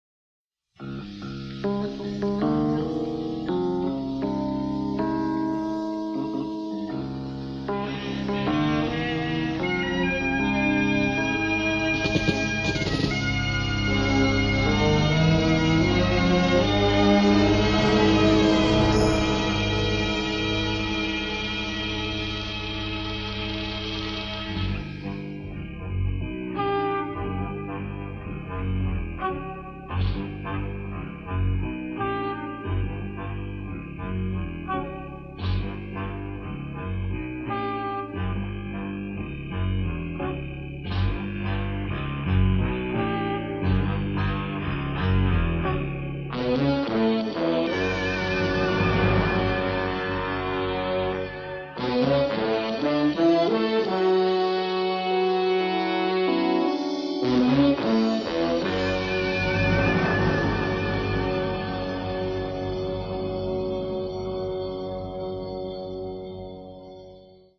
innovative use of synthesizers combined with jazz elements